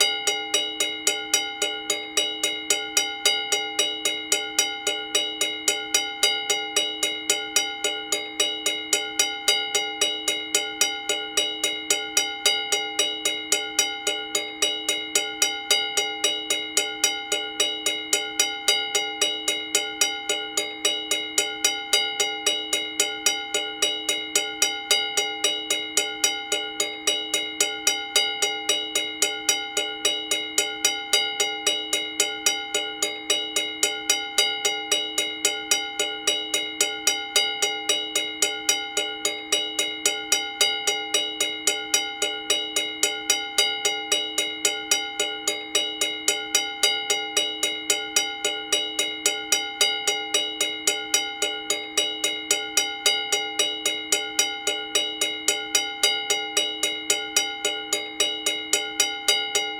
Nu had ik een echte richtmicrofoon tot m'n beschikking en een digitaal opname-apparaat (dat dat dan een videocamera is doet niet echt ter zake)
In Rosmalen, grenzend aan het station, is een overweg die perfect is voor deze opname: slechts 1 lage bel die blijft 'tingen' nadat de bomen gesloten zijn.
Overwegbel laag, 1,37 MB, MP3 Mono, 48000 Hz.
overwegbel Rosmalen.mp3